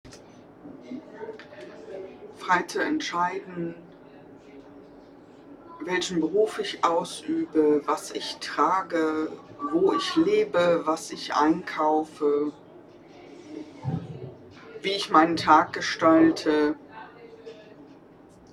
Standort der Erzählbox:
MS Wissenschaft @ Diverse Häfen
Standort war das Wechselnde Häfen in Deutschland.